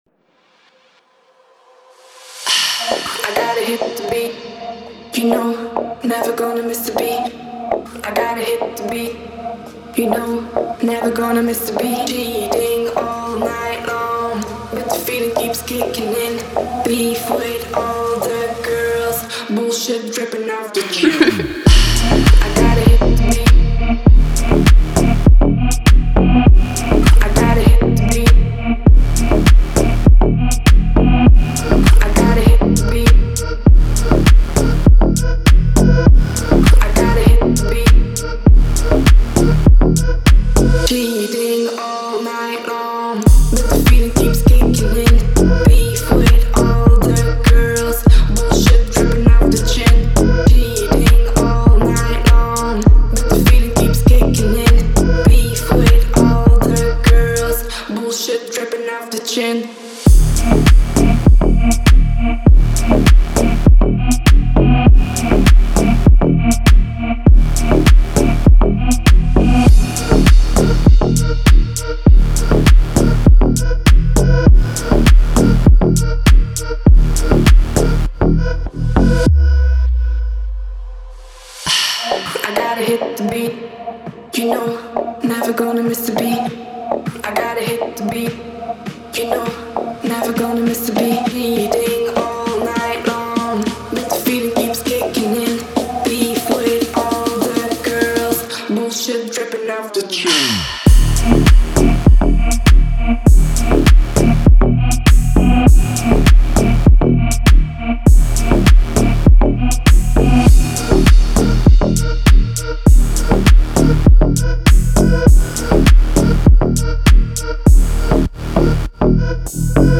Категория: Deep House музыка